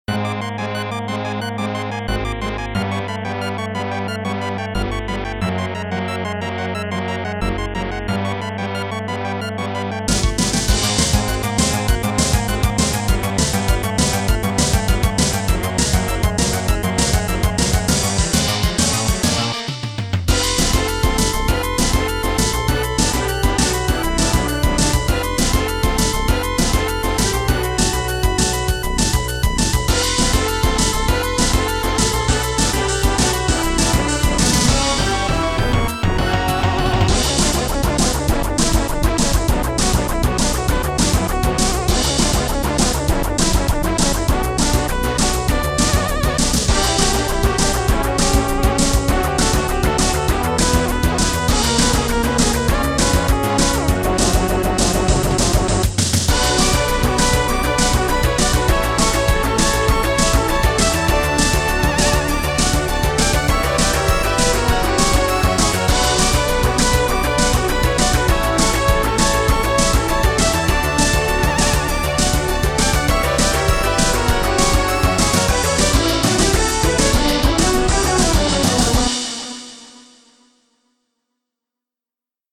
BPM200
Audio QualityPerfect (Low Quality)